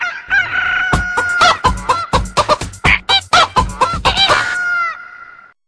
Cockadoodle.mp3